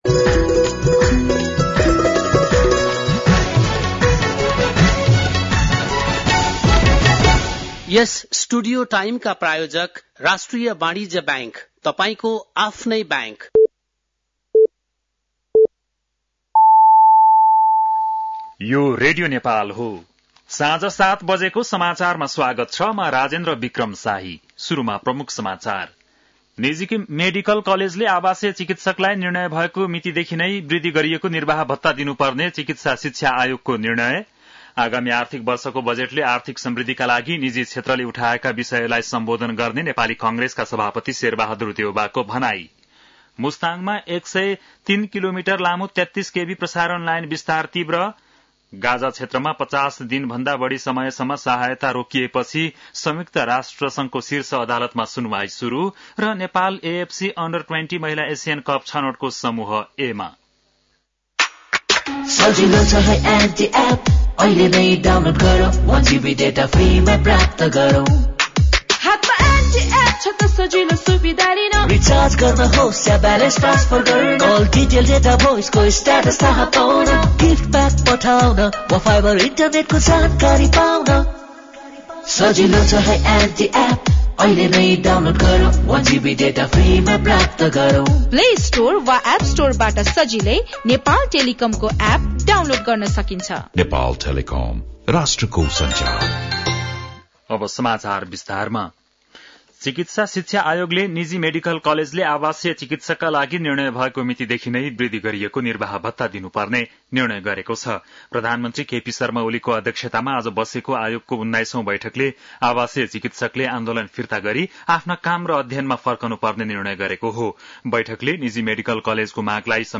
बेलुकी ७ बजेको नेपाली समाचार : १५ वैशाख , २०८२